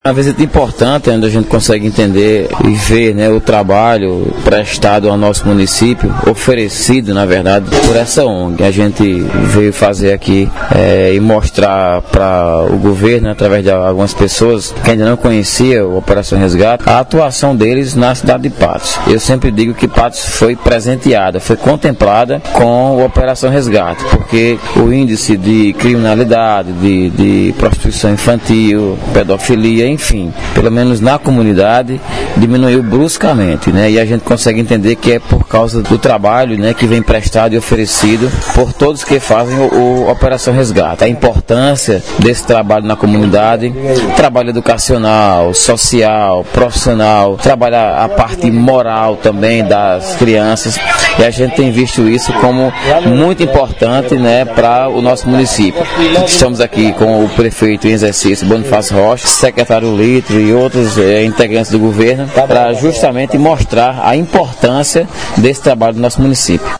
Fala do vereador Sales Júnior –